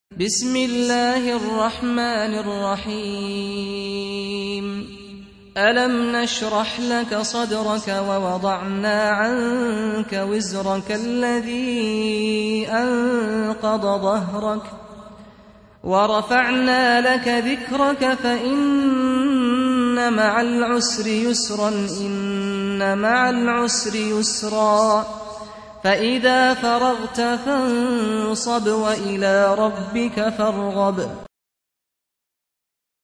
94. Surah Ash-Sharh سورة الشرح Audio Quran Tarteel Recitation
Surah Repeating تكرار السورة Download Surah حمّل السورة Reciting Murattalah Audio for 94. Surah Ash-Sharh سورة الشرح N.B *Surah Includes Al-Basmalah Reciters Sequents تتابع التلاوات Reciters Repeats تكرار التلاوات